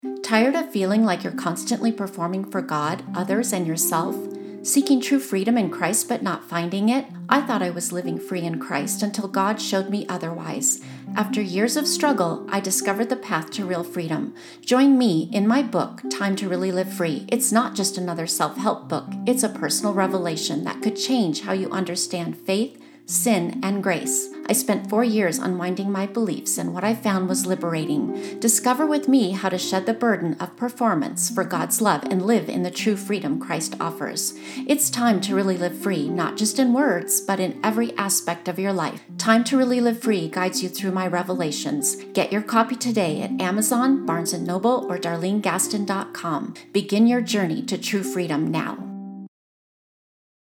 Radio Ad For Time To Really Live Free